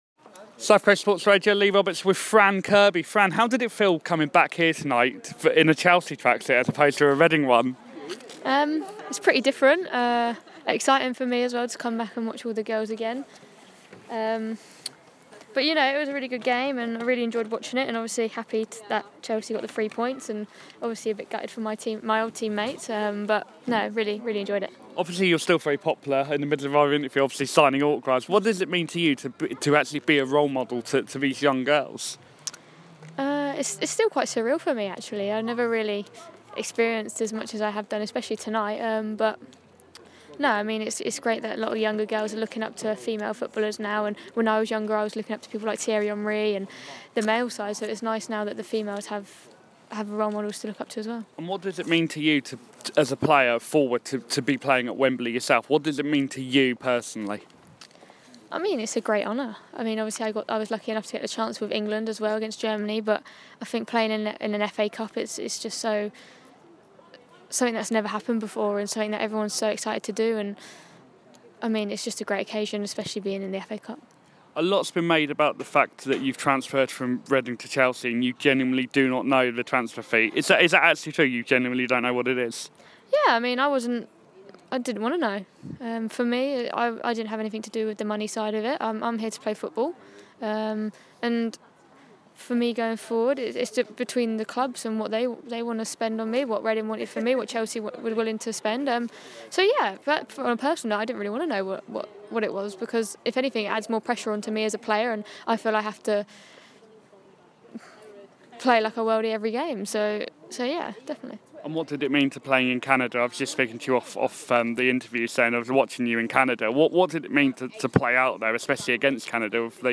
Fran Kirby interview